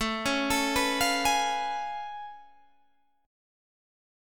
Listen to A+M9 strummed